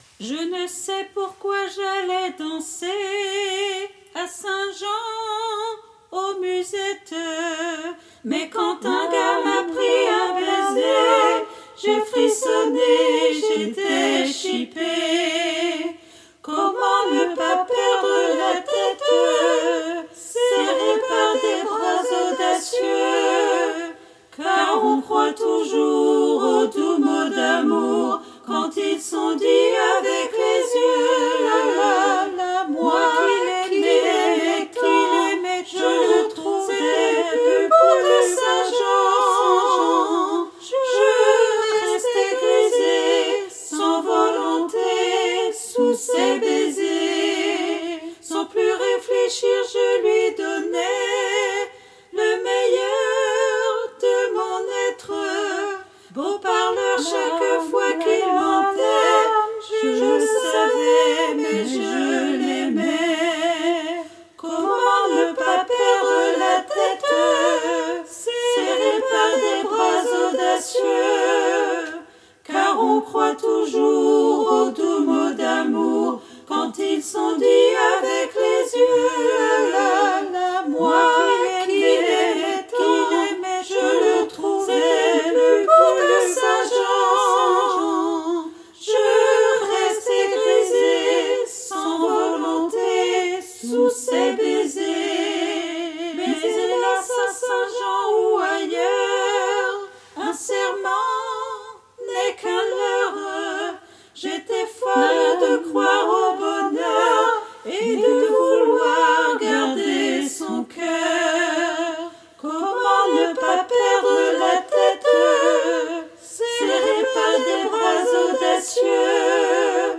MP3 versions chantées
Toutes les voix